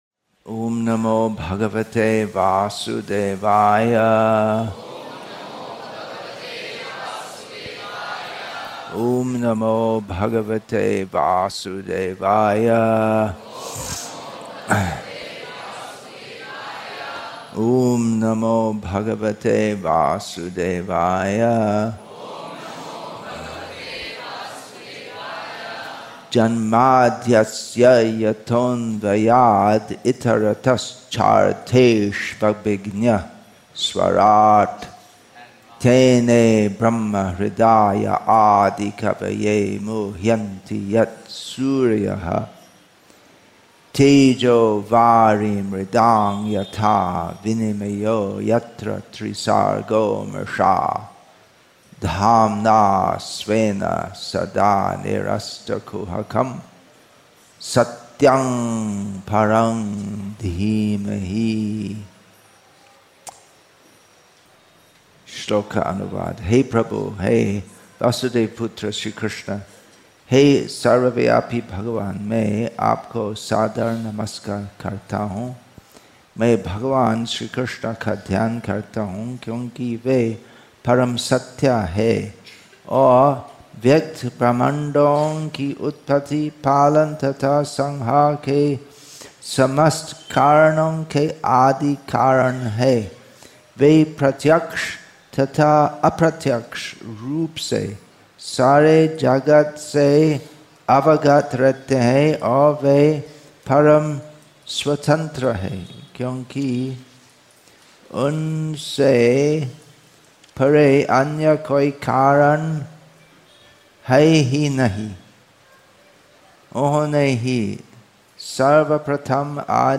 Going Beyond The Neophyte Platform April 4, 2021 Śrīmad-Bhāgavatam English with தமிழ் (Tamil) Translation; VelloreTamil Nadu , Śrīmad-Bhāgavatam 1.1.1 1 h 2 m 30 s 60.00 MB Download Play Add To Playlist Download